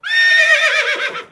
c_whorse_atk2.wav